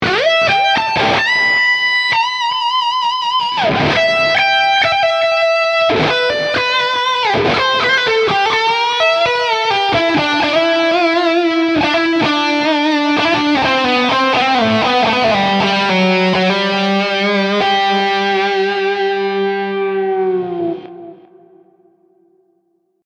Tutte le clip audio, sono state registrate con testata a Marshall JCM800 sul canale Low e cassa 2×12 equipaggiata con altoparlanti Celestion Creamback, impostata su un suono estremamente clean.
Clip 4 – aggiunto Echo On Delay.
Chitarra: Fender Stratocaster (pickup al ponte)
Mode: Lead 2
Gain: 8/10